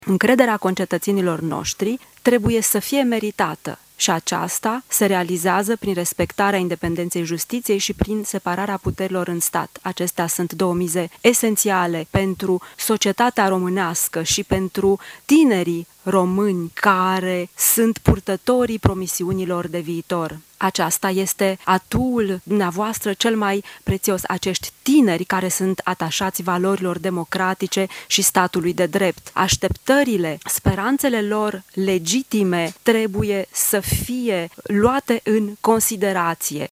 “România a îndeplinit criteriile pentru Schengen și trebuie să fie primită în spațiul de liberă circulație cât mai repede posibil” – spune de la tribuna Parlamentului, la București, președintele Comisiei Europene.